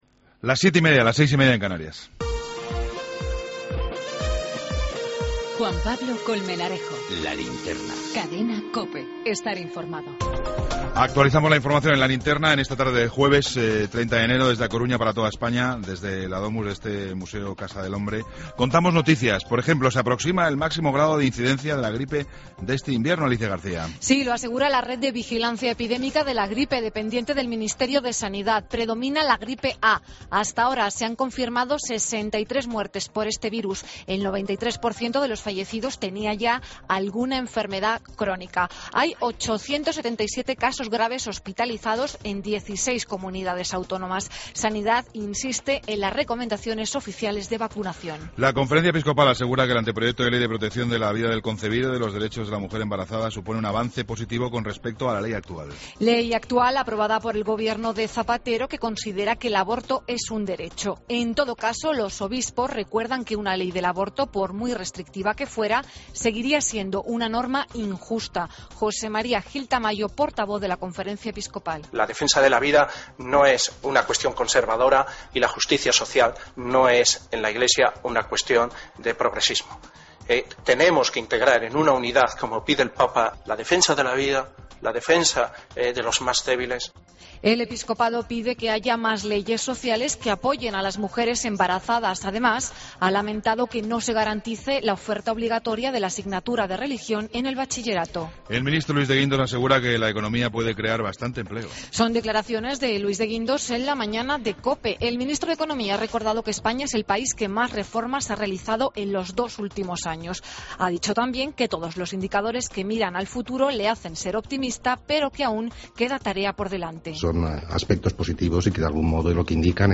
Rueda de Corresponsales.
Entrevista a Enrique Losada, Presidente de la Autoridad Portuaria de A Coruña.